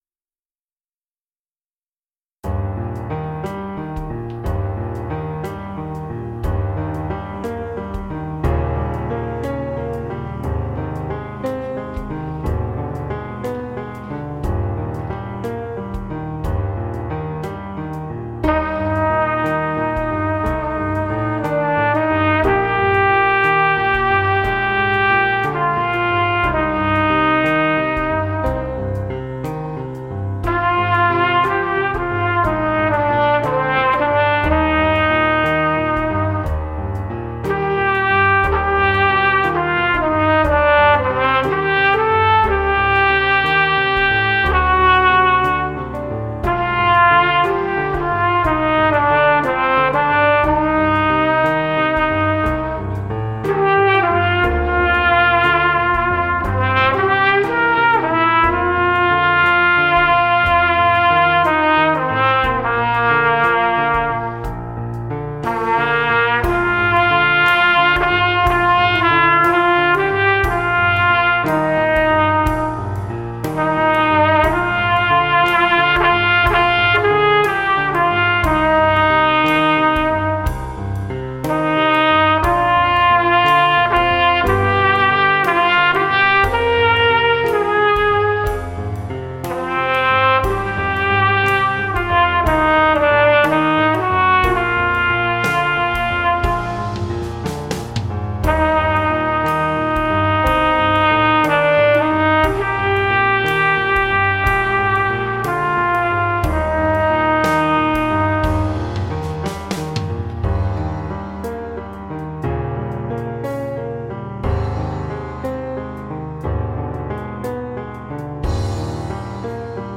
TROMBA SOLO • ACCOMPAGNAMENTO BASE MP3
Base - Concerto